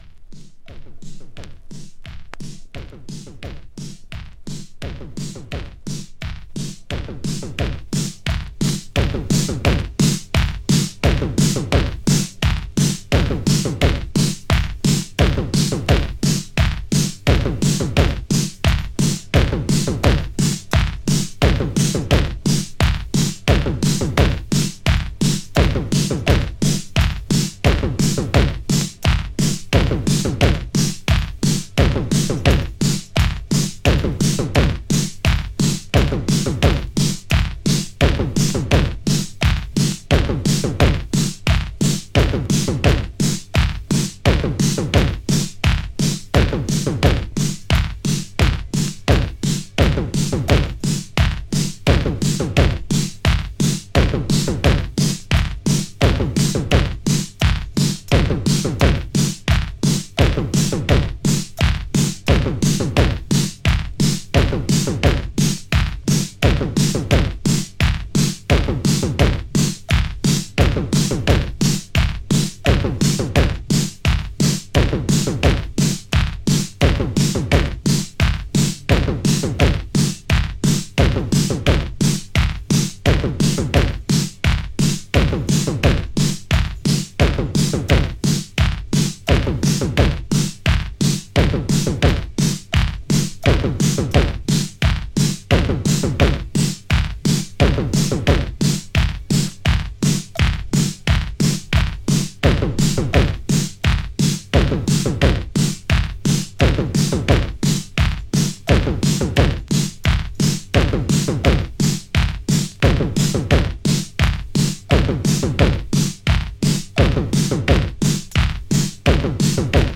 NEW BEAT！
エレクトリックなサウンドとオリエンタルなアプローチによるニュービート・ナンバー！